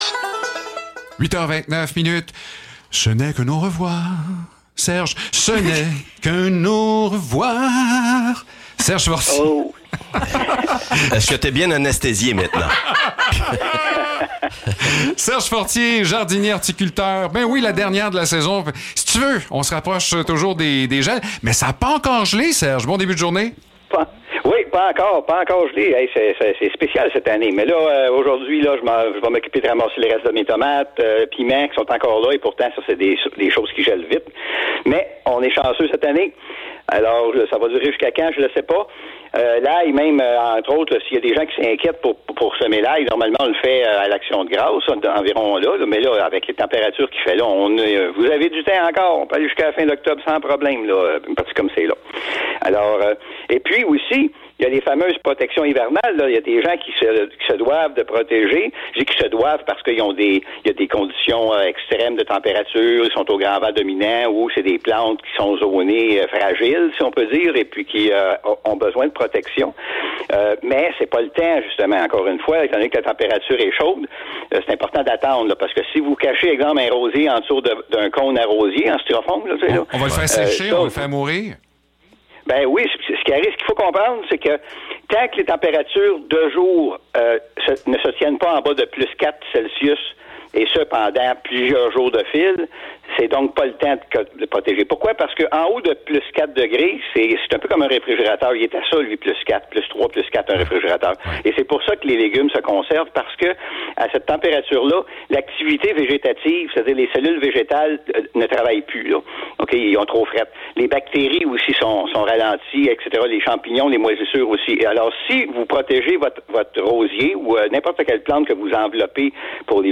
Chronique horticulture